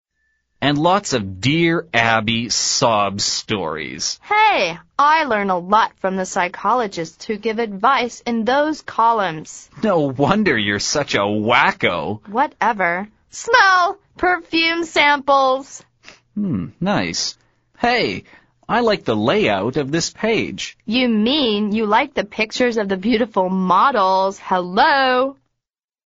美语会话实录第104期(MP3+文本):You're such a wacko